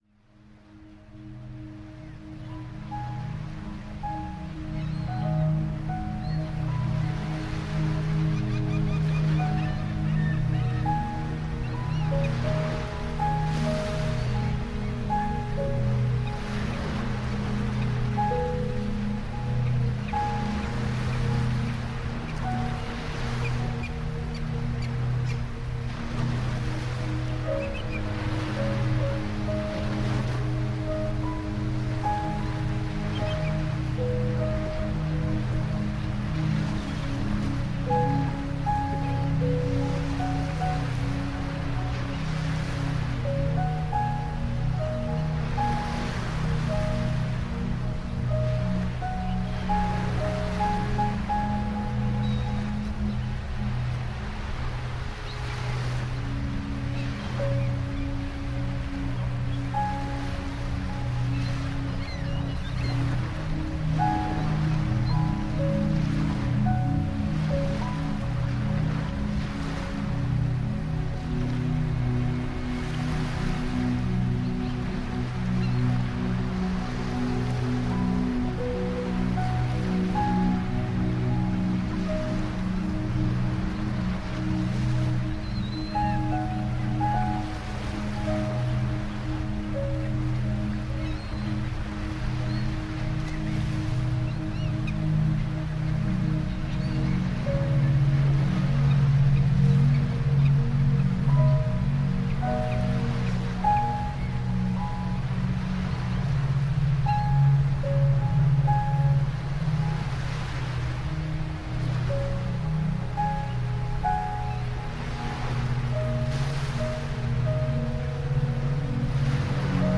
• Качество: Хорошее
🎶 Детские песни / Музыка детям 🎵 / Музыка для новорожденных